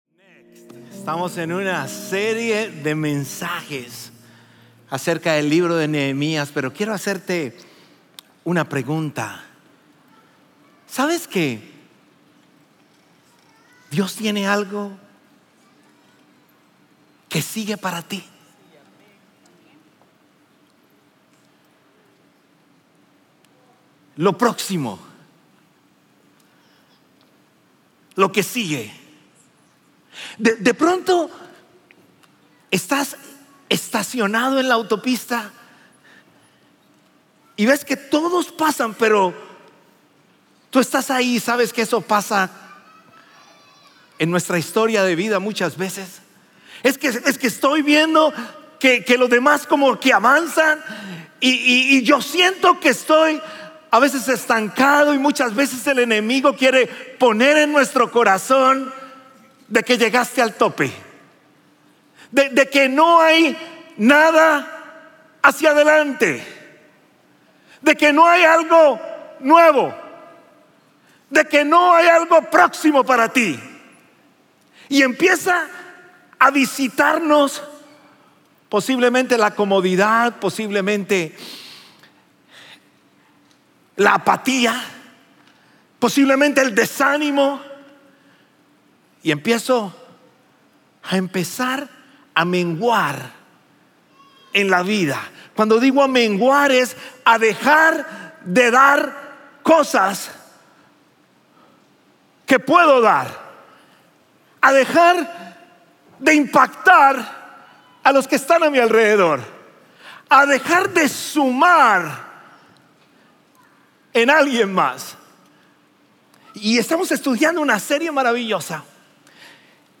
Sermones Conroe – Media Player